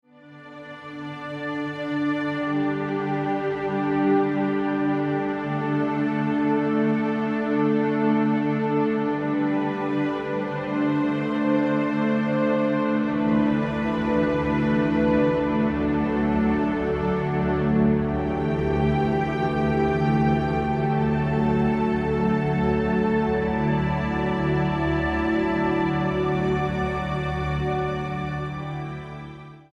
for string orchestra
Description:Classical; ensemble music; orchestral work
Instrumentation:String orchestra